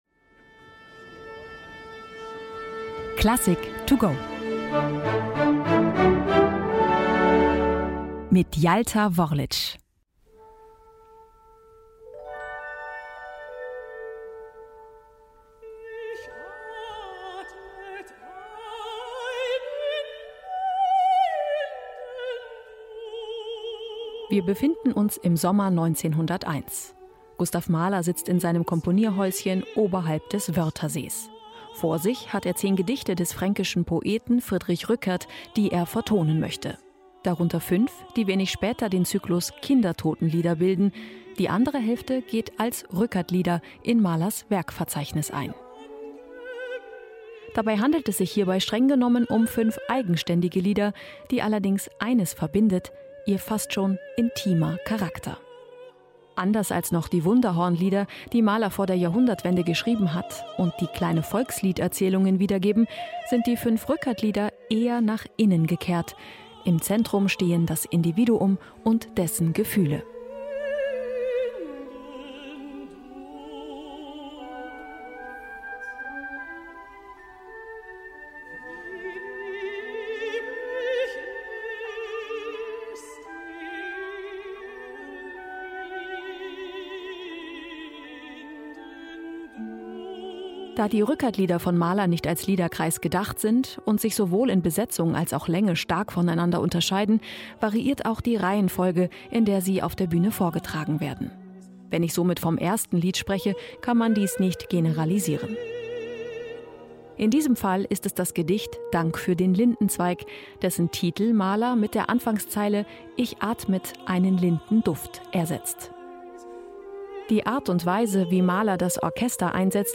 Werkeinführung digital und für unterwegs